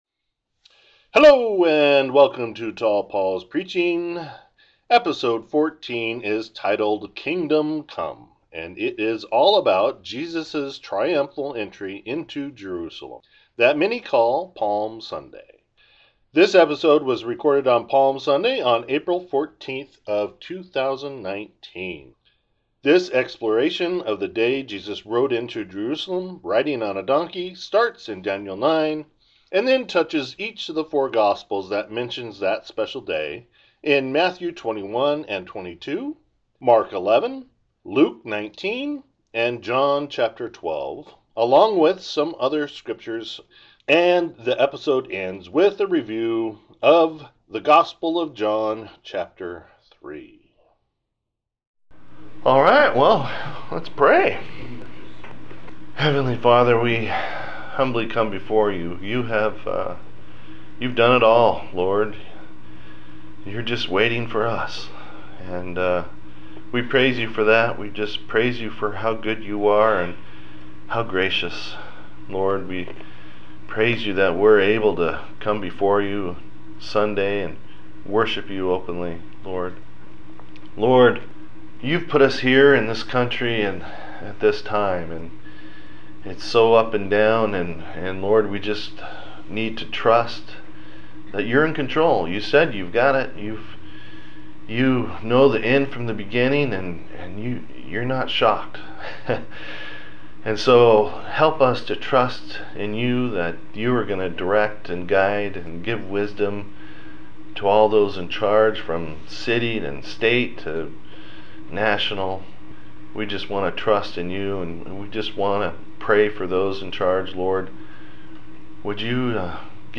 Topical sermons targeted toward a mature Christian audience since 2018. Personally recorded messages focused on encouragement, hope, and finishing well.